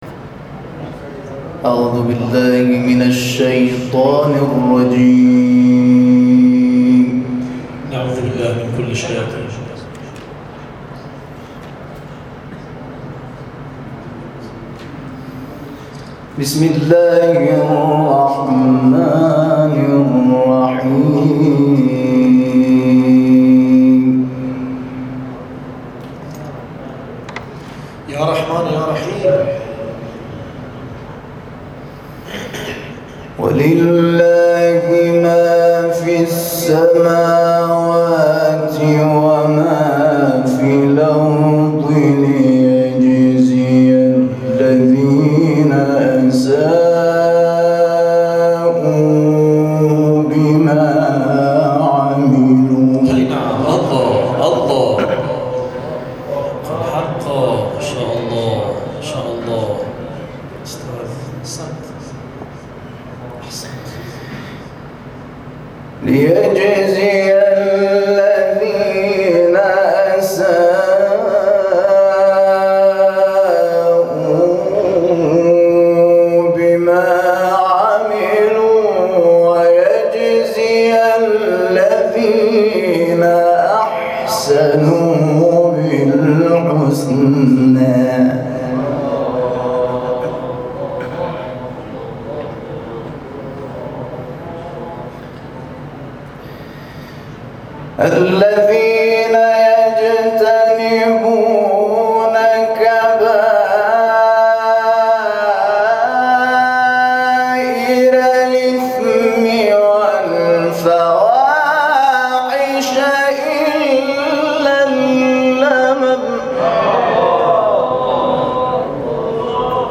مرحله مقدماتی دور سوم جشنواره تلاوت‌های مجلسی پایان یافت + صوت و عکس
تلاوت